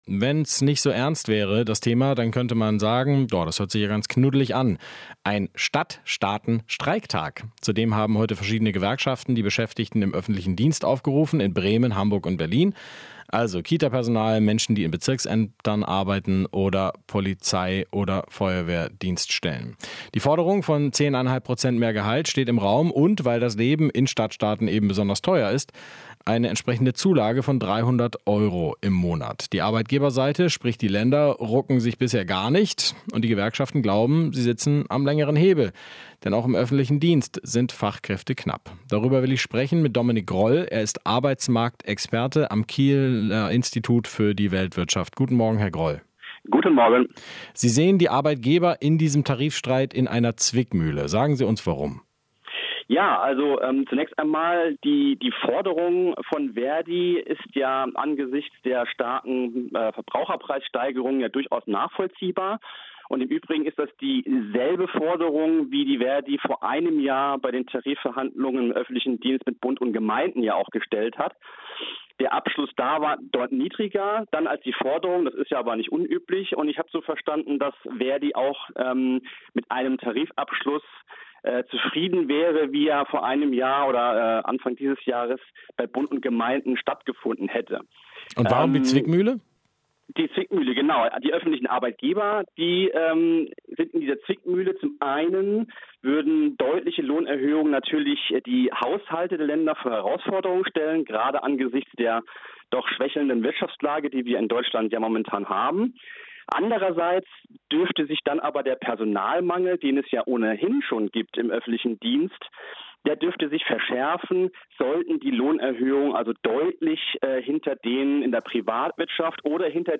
Interview - Arbeitsmarktexperte: Länder bei Tarifverhandlungen in der Zwickmühle